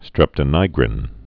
(strĕptə-nīgrĭn)